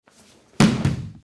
На этой странице собраны звуки, связанные с дзюдо: крики соперников, шум татами, команды тренера.
Звук падения спортсмена на мат во время схватки по дзюдо